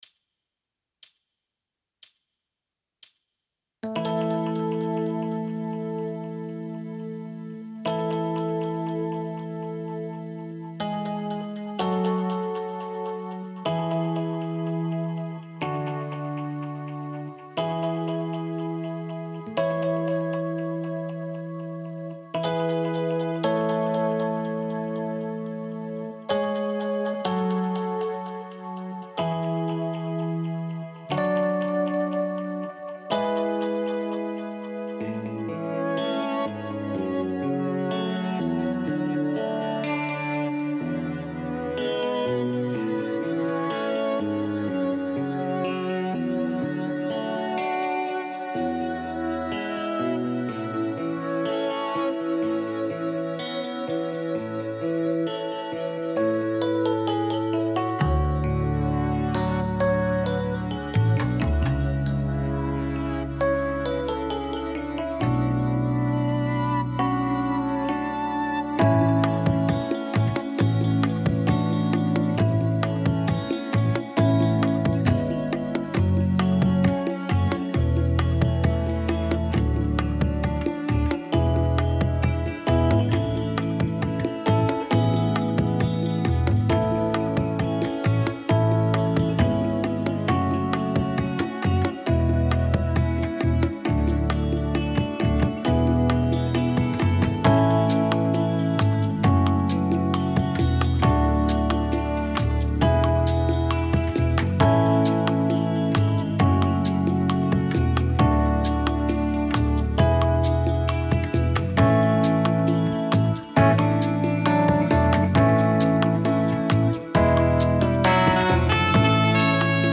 минусовка версия 212411